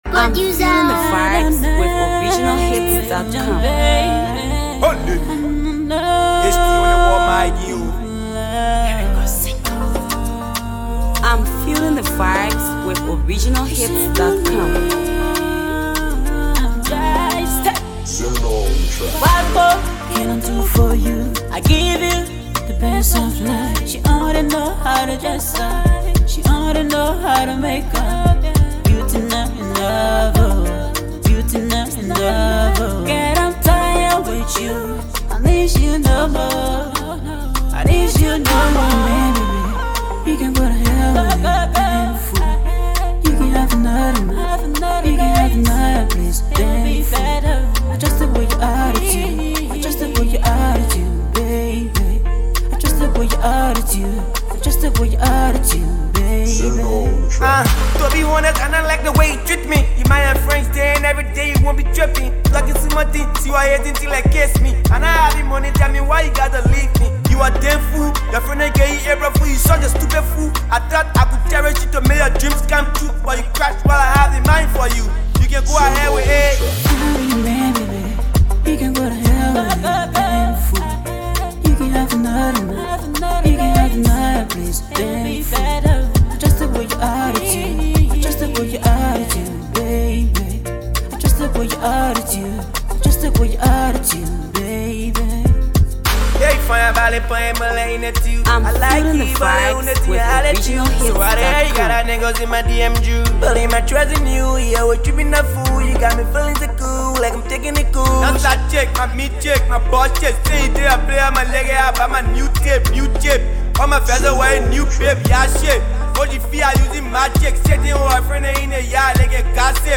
Multi-talented uprising trap artist